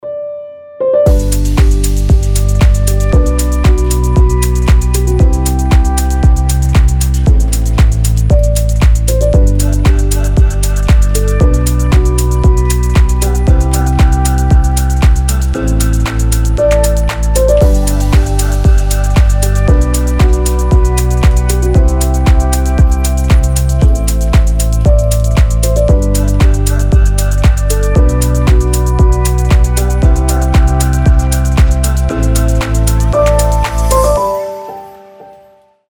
• Качество: 320, Stereo
атмосферные
мелодичные
без слов
chillout
пианино
расслабляющие